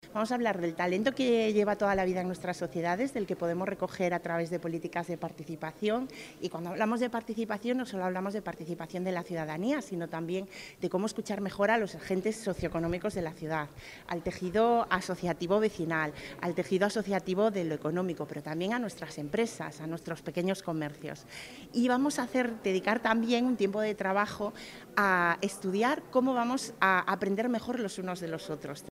El alcalde de Ciudad Real, Francisco Cañizares, y la concejal de Promoción Económica, Yolanda Torres, han asistido a la inauguración del evento celebrada en el Museo del Quijote.